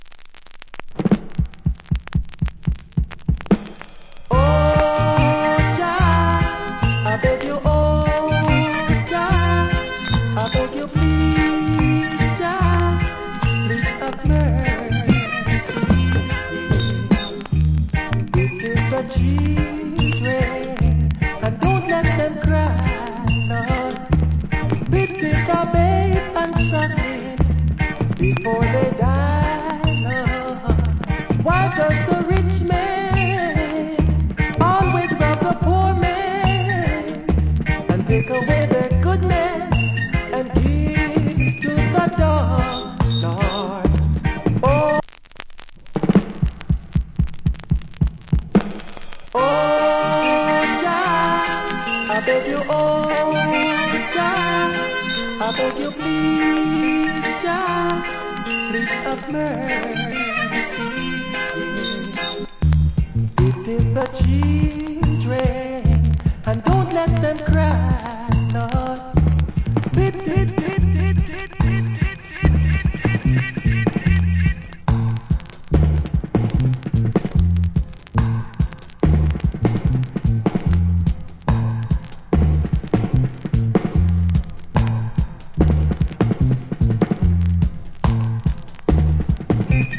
Nice Roots Vocal.